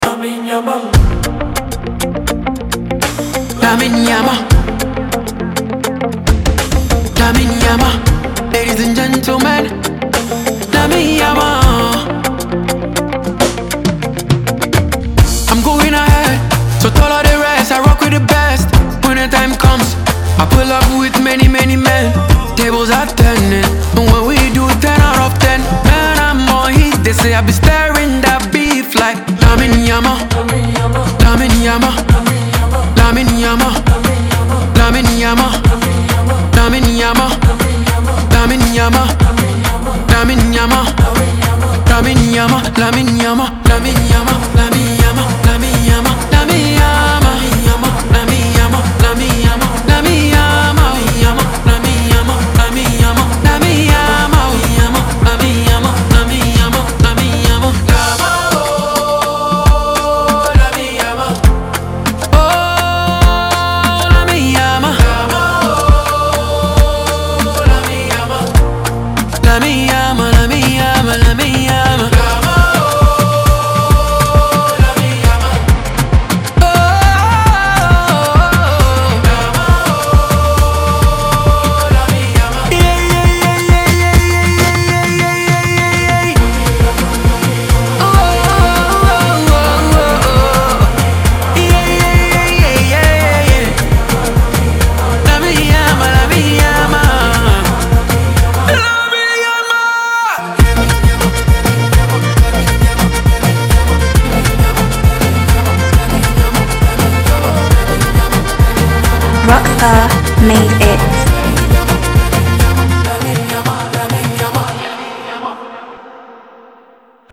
a vibrant Afrobeat banger
catchy melodies, energetic vocals